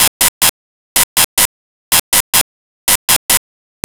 Telemetry Loop
Telemetry Loop.wav